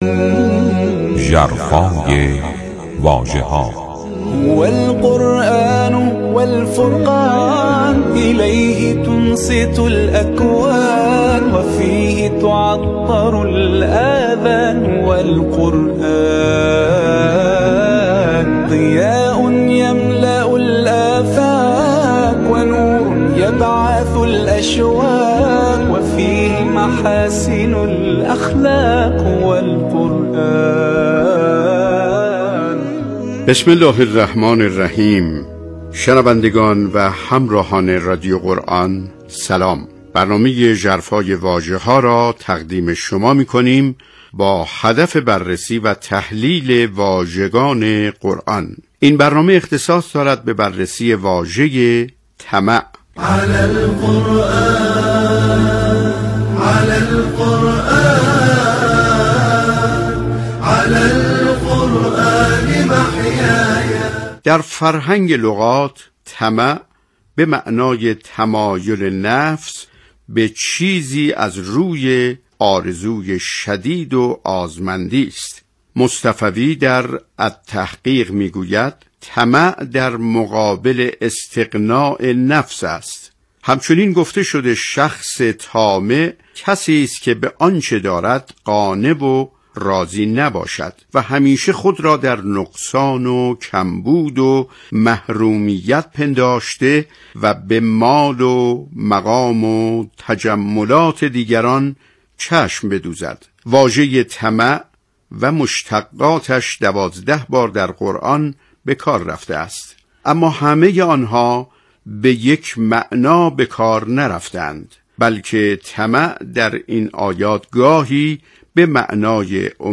«ژرفای واژه‌ها» عنوان برنامه کوتاه رادیو قرآن است که طی آن به بررسی و تحلیل واژگان قرآن پرداخته می‌شود.